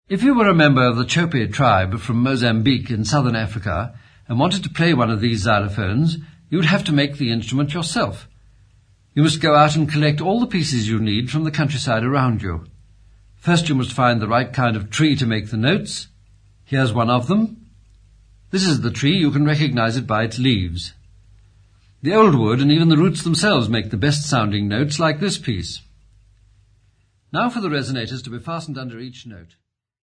Narration
Xylophones
Singing
Radio broadcast
Broadcast copy of the original entitled 'Chopi Xylophones and Zulu Christian Dances', for the television film programme broadcast by the British Broadcasting Corporation
44100Hz 24Bit Stereo